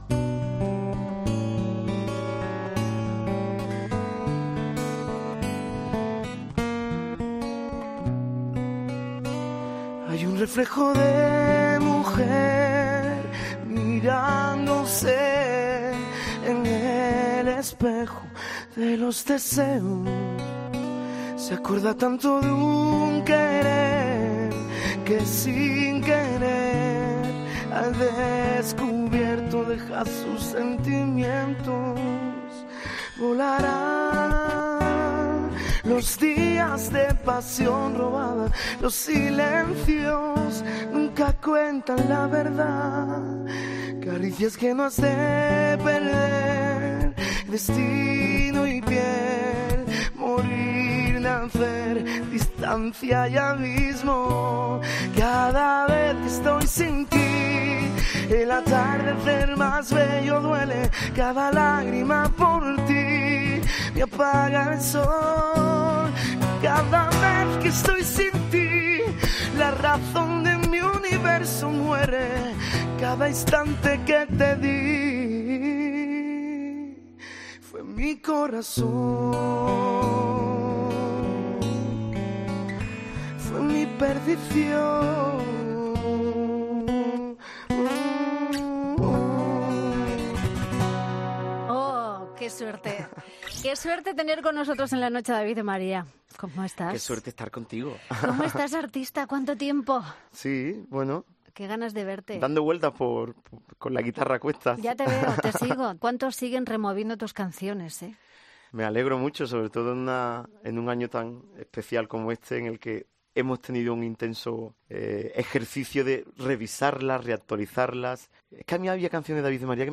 Escucha la entrevista al cantante y compositor David DeMaría en 'La Noche de COPE con Rosa Rosado'